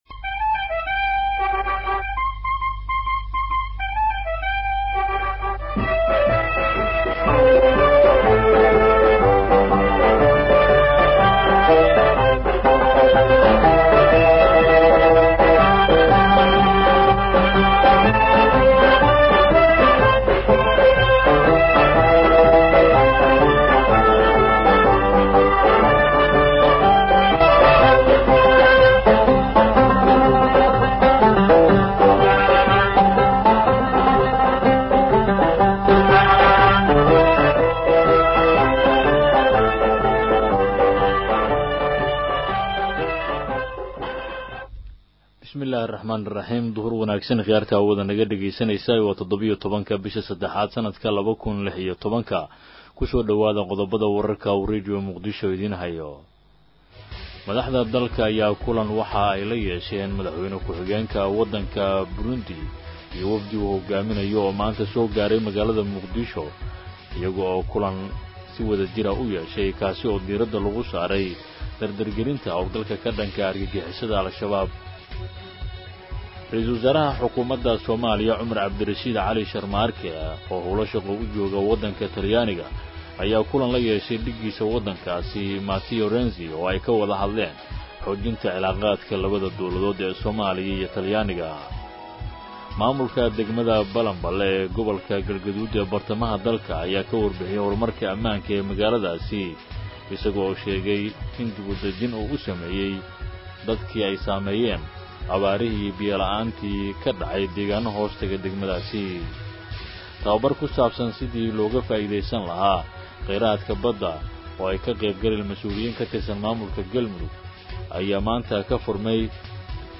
Dhageyso warka duhurnimo ee Radio Muqdisho